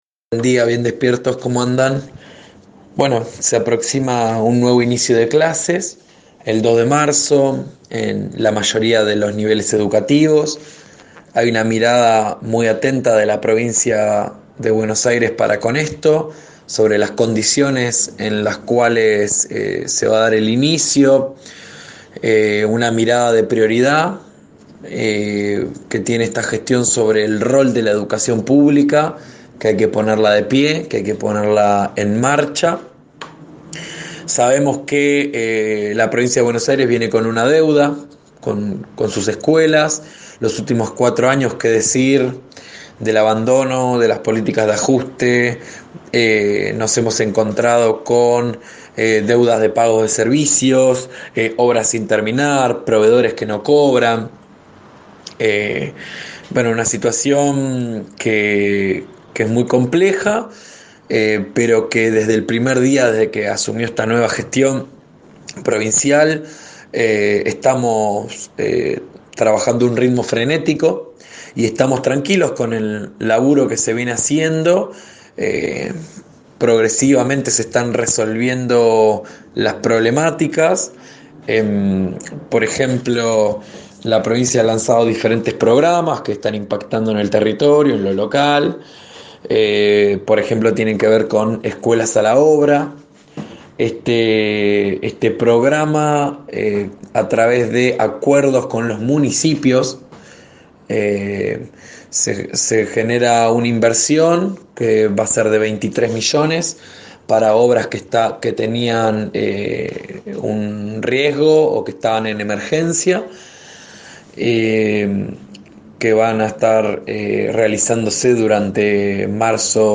Alejandro Moviglia, consejero escolar del Frente de Todxs, habló sobre la proximidad del ciclo lectivo y la situación de los establecimientos educativos.